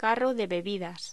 Locución: Carro de bebidas
voz